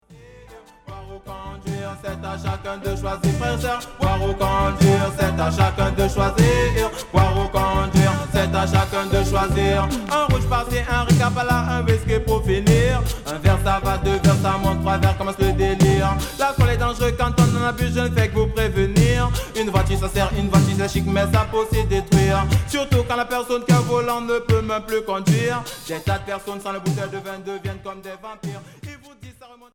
Reggae rub a dub Premier 45t retour à l'accueil